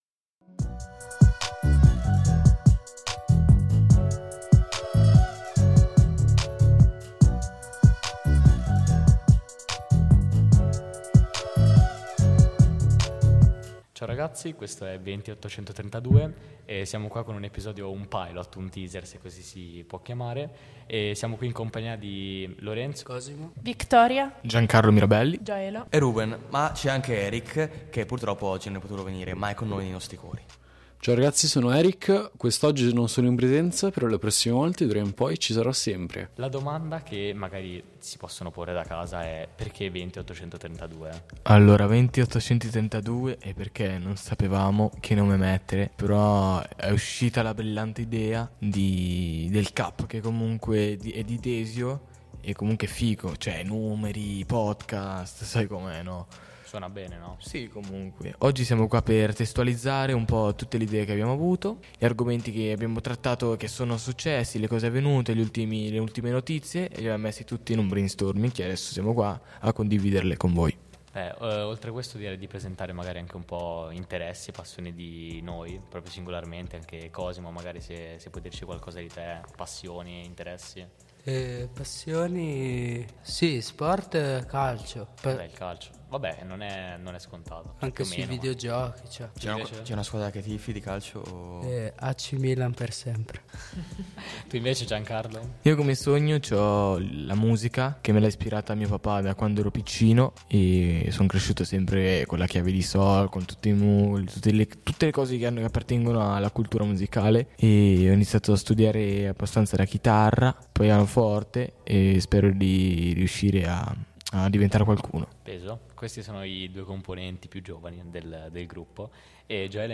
Siamo un gruppo di ragazzi del territorio desiano, dai 14 ai 23 anni: universitari, commessi, appassionati di cinema, trap…
Il tutto condito da un freetalk nel quale affrontiamo il tema e lo portiamo sul piano della nostra esperienza e del nostro vissuto.